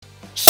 Play, download and share Tsch! original sound button!!!!
tsssh.mp3